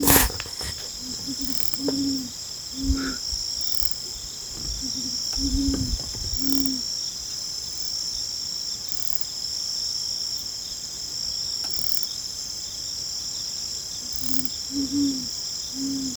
Jacurutu (Bubo virginianus)
Nome em Inglês: Great Horned Owl
Localidade ou área protegida: Parque Nacional El Impenetrable
Condição: Selvagem
Certeza: Observado, Gravado Vocal